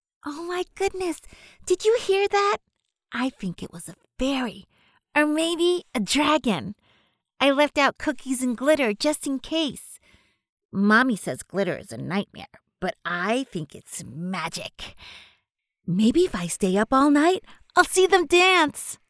Character Samples
Little-Girl.mp3